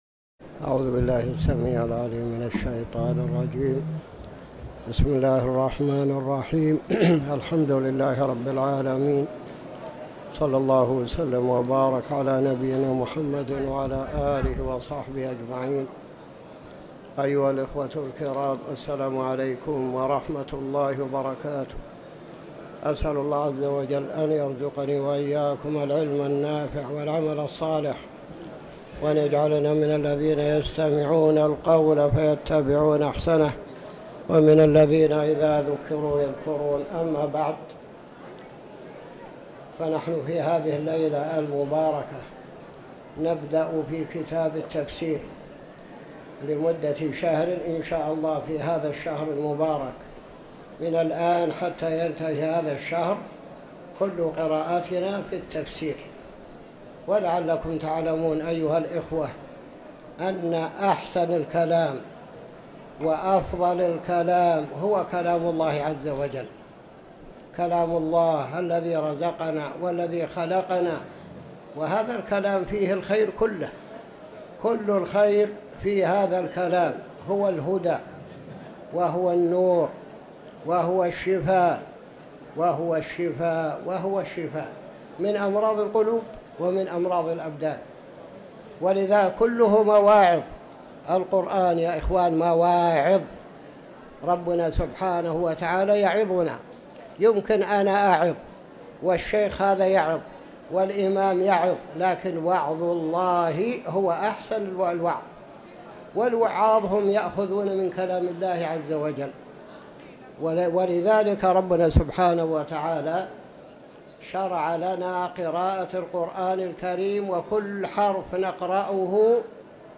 تاريخ النشر ٢ رجب ١٤٤٠ هـ المكان: المسجد الحرام الشيخ